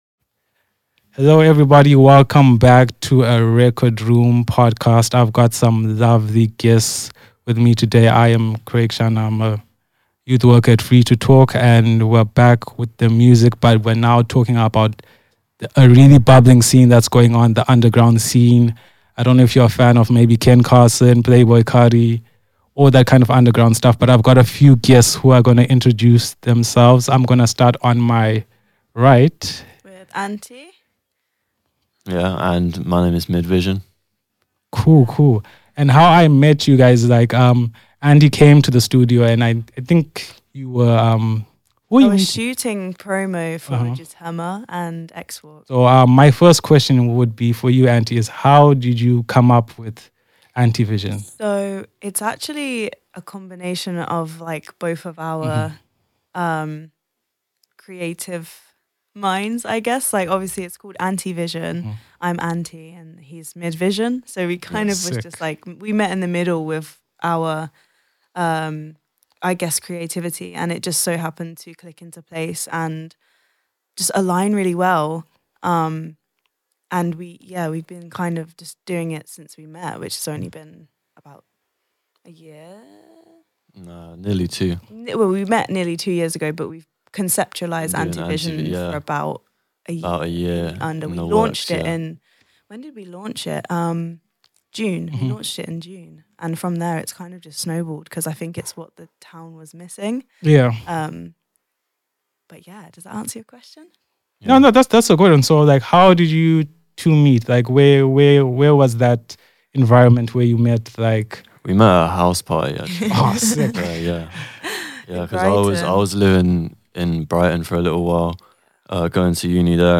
We sat down at Rekordshop to talk about how this came to be: The frustration of being overlooked in a city that pretends nothing is happening.